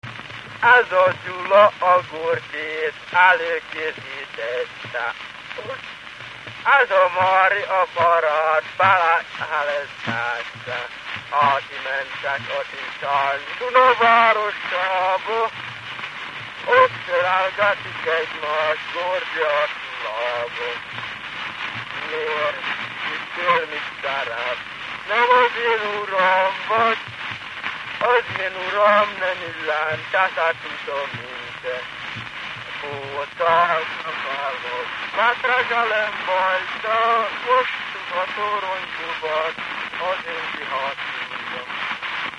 Dunántúl - Zala vm. - Hottó
Stílus: 6. Duda-kanász mulattató stílus